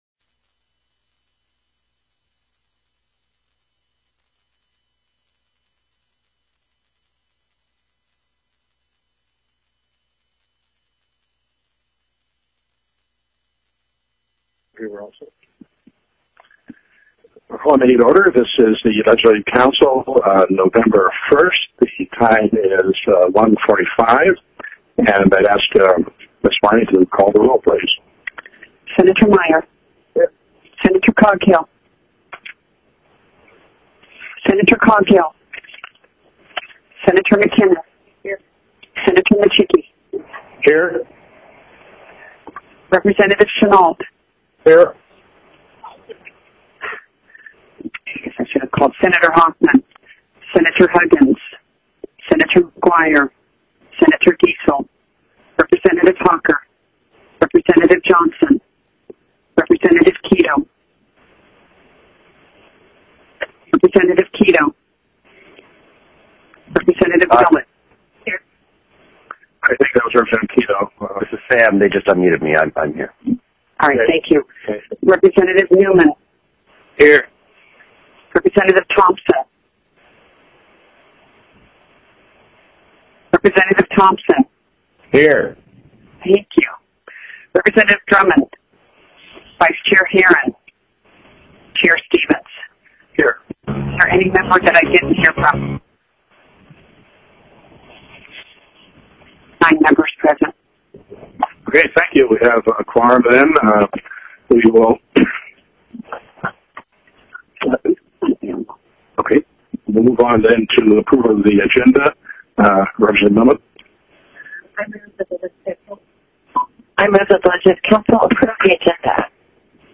CHAIR GARY STEVENS called the Legislative Council meeting to order at 1:45 p.m. in the Anchorage Legislative Information Office Large Conference Room.
Participating via teleconference were Senators Coghill and Micciche; and Representatives Chenault, Kito, Neuman, and Thompson, alternate.